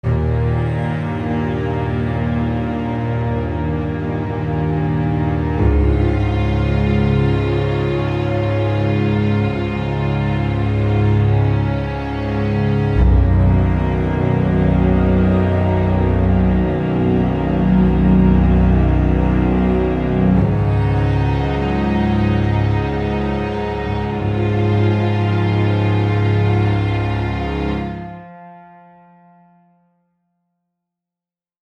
I have started learning how to create music using FL Studio exactly one day ago.
I like the music, it’s so calming!